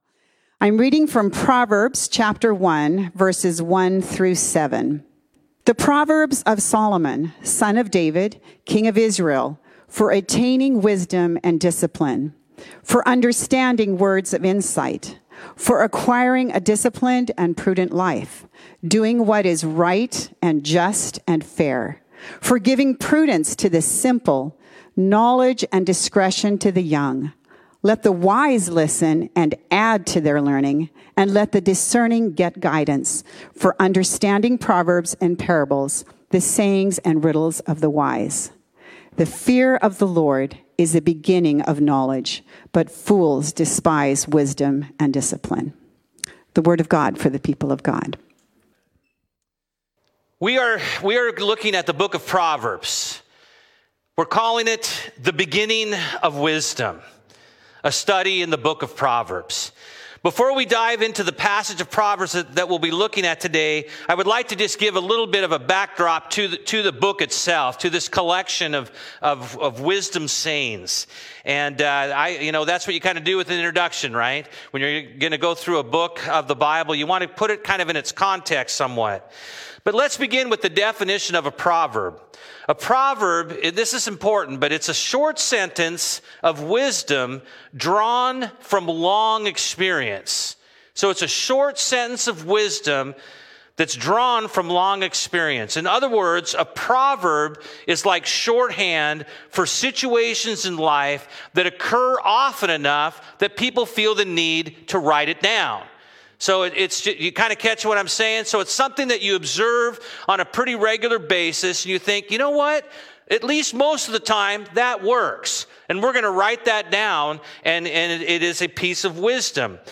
Bible Text: Proverbs 1:1-7 | Preacher